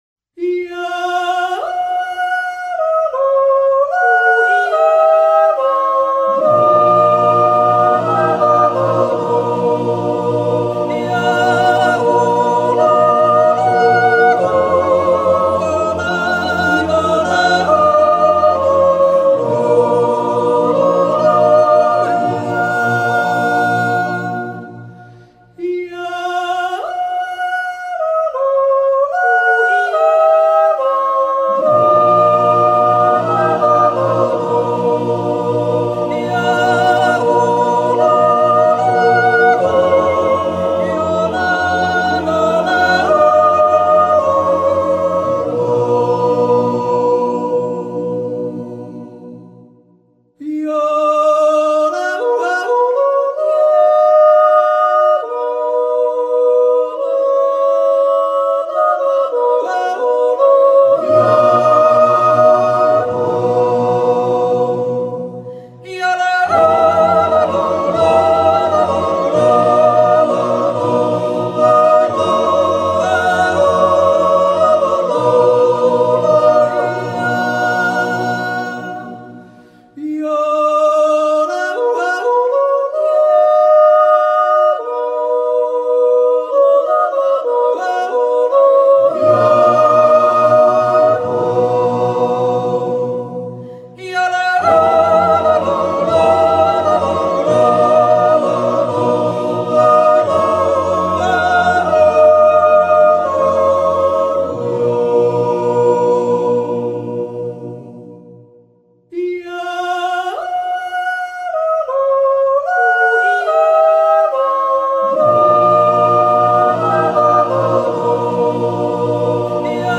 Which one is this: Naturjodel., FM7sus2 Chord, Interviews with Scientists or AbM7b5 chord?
Naturjodel.